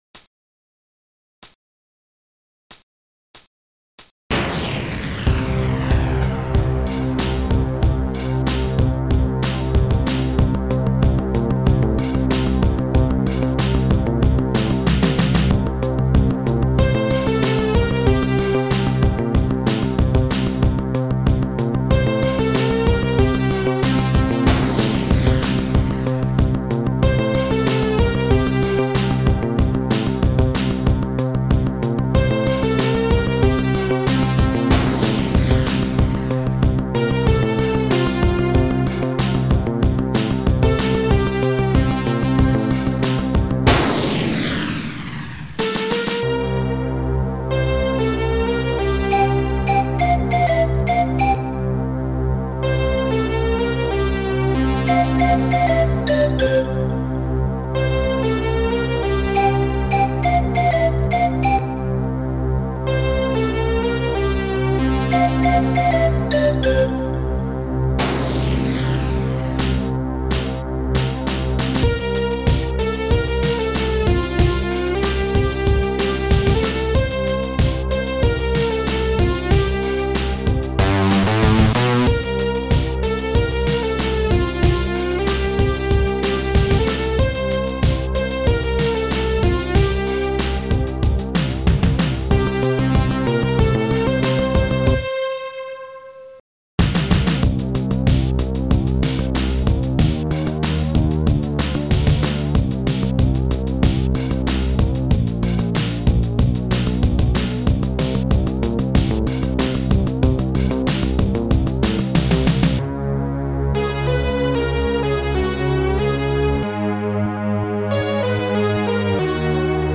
Soundtracker 15 Samples